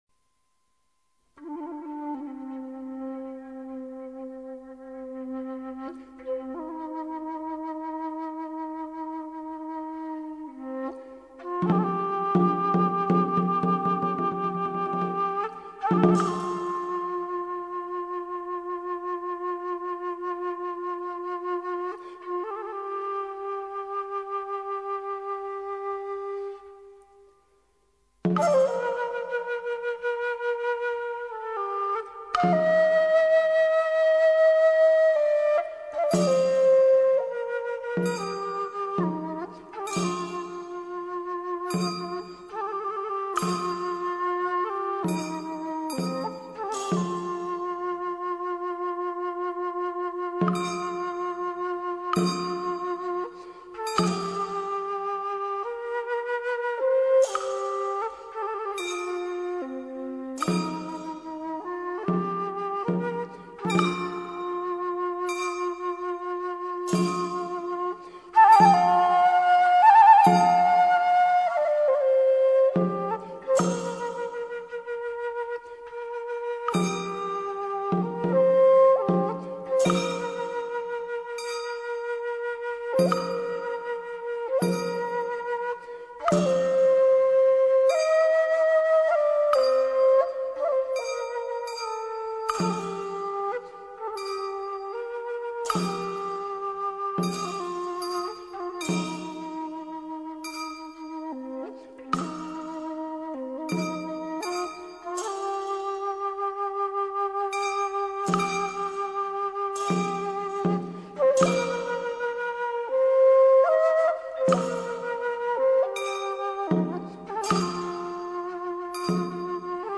大师典范演绎，名盘呈现，情归丝弦，源自音乐之力量！